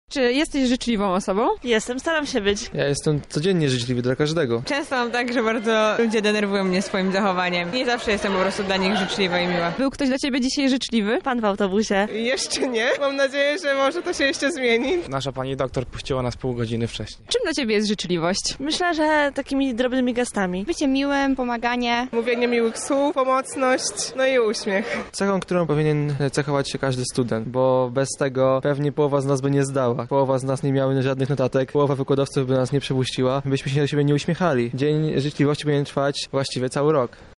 Zapytaliśmy mieszkańców Lublina czy są życzliwi i czy doświadczają na co dzień tego, czym chcą obdarzyć innych.